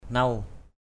/nau/ (c.) rồi, mất rồi! chaor nuw _S<R n~| trật rồi. nuw-nuw n~|-n~| biền biệt. nao nuw nuw _n< n~|-n~| đi biền biệt.